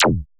ZAP ME  1.wav